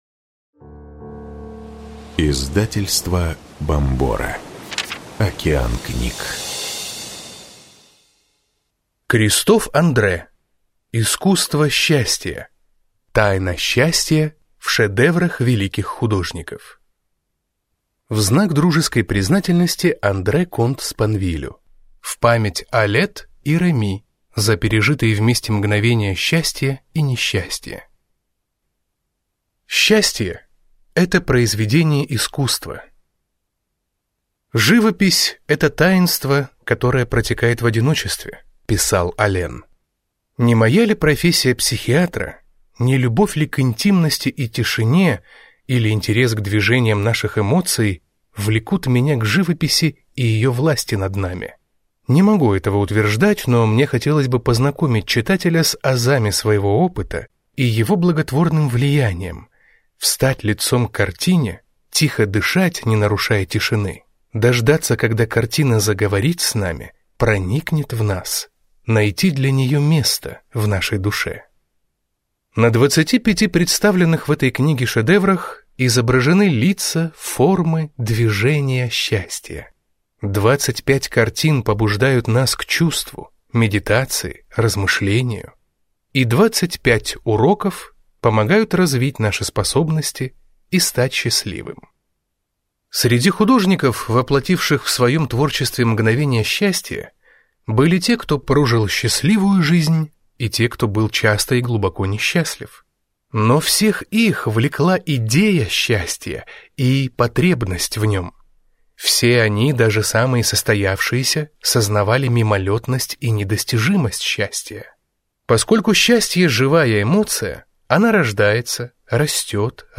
Аудиокнига Искусство счастья. Тайна счастья в шедеврах великих художников | Библиотека аудиокниг